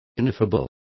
Complete with pronunciation of the translation of ineffable.